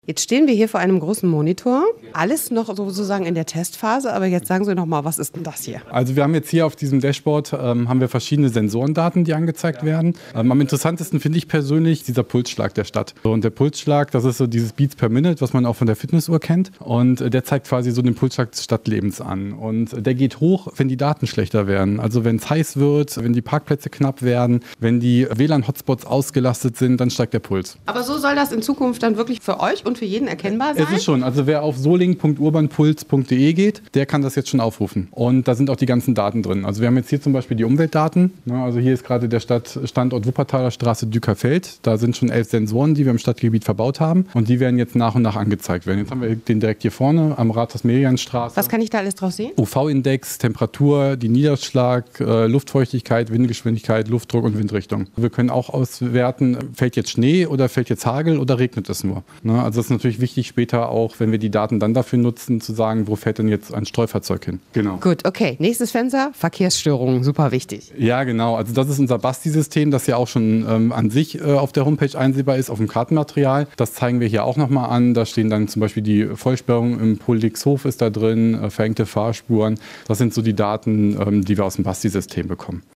int_smartcity_1.mp3